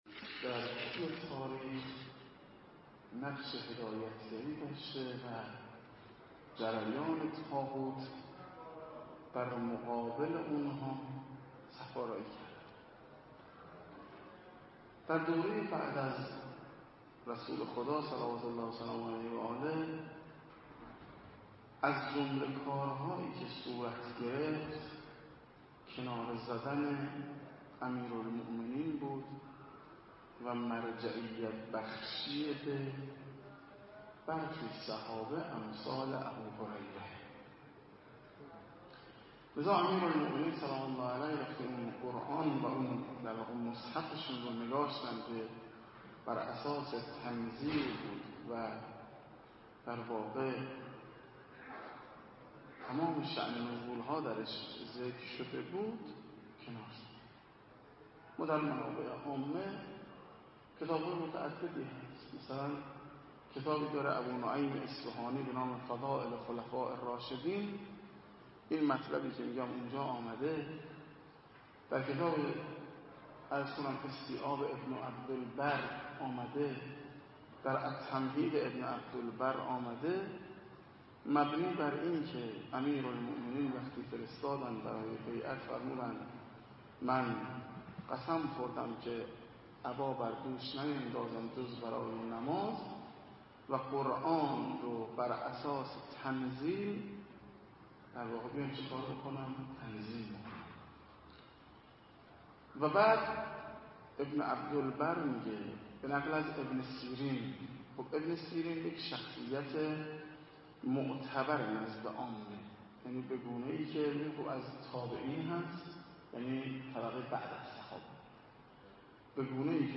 مراسم عزاداری شهادت حضرت موسی بن جعفر ع